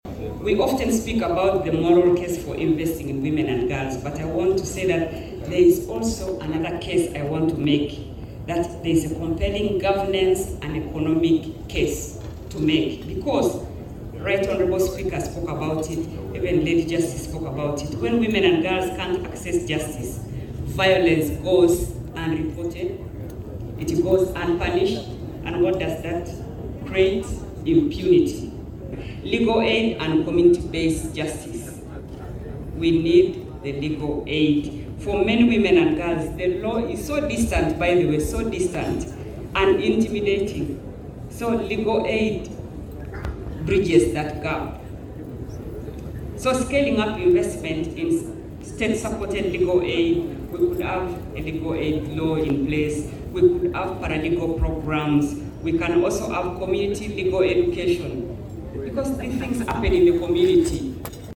This call was made at Parliament on Friday, 06 March 2026 during the Parliamentary Women’s Day breakfast meeting in commemoration of this year’s International Women’s Day.
Principal Judge,  Justice Jane Frances Abodo, who was the guest speaker could not agree more with Bamugemereire on the urgency of legal aid services.